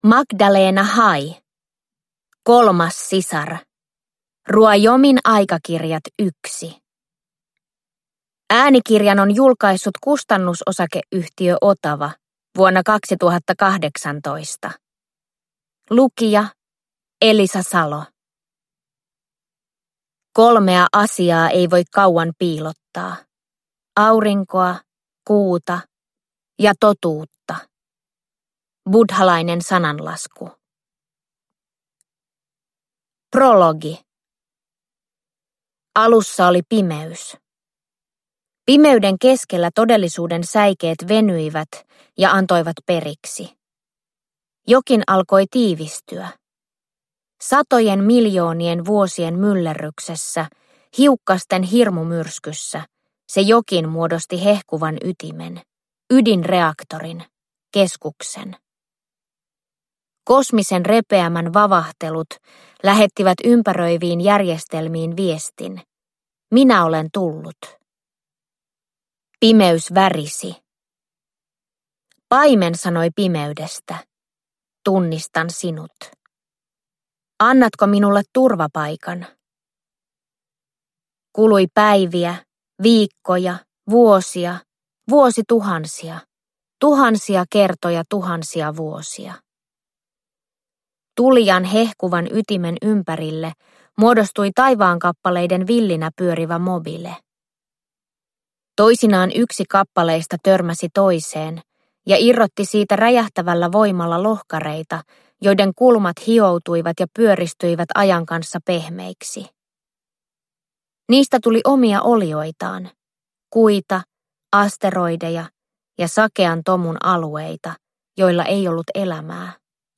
Kolmas sisar – Ljudbok – Laddas ner